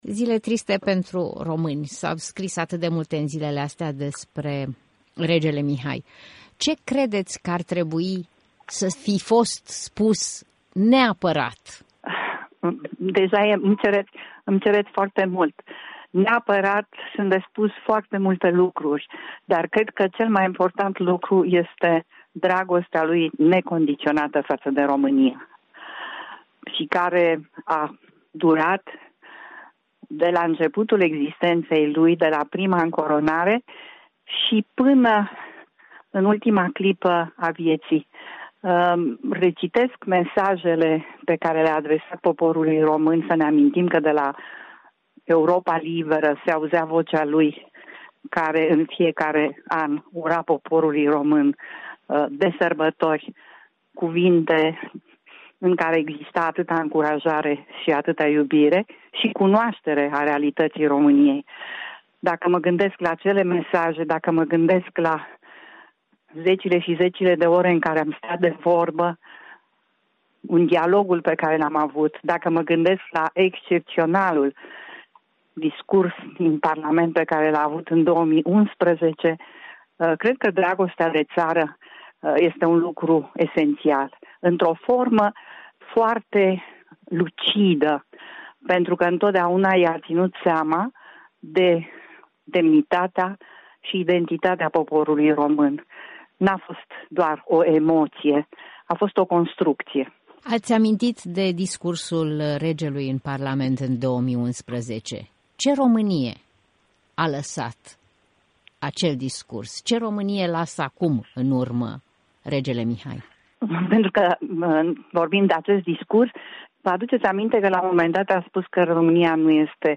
O evocare a regretatului rege Mihai într-un interviu cu scriitoarea stabilită astăzi la New York.